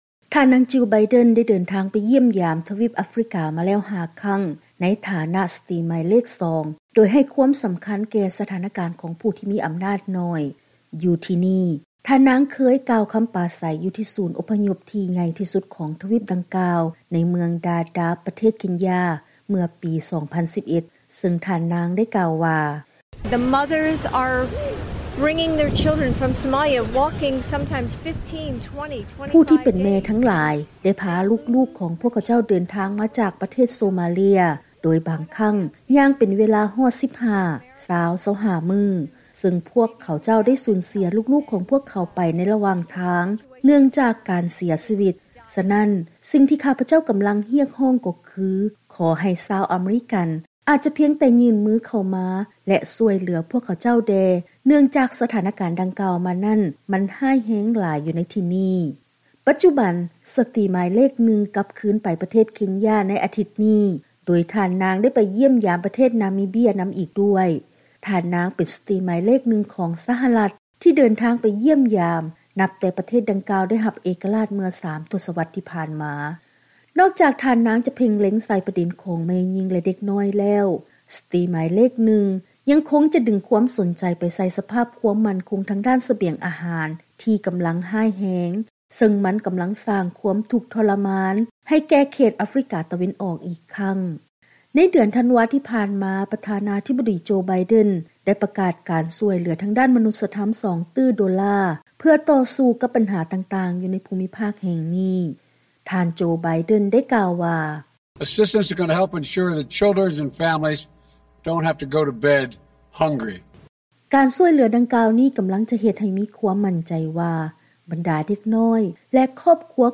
ເຊີນຮັບຟັງລາຍງານກ່ຽວກັບ ການເດີນທາງໄປຢ້ຽມຢາມປະເທດເຄັນຢາ ເທື່ອທໍາອິດໃນຖານະສະຕີໝາຍເລກນຶ່ງຂອງ ທ່ານນາງຈີລ ໄບເດັນ